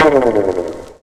TEK BOING 07.wav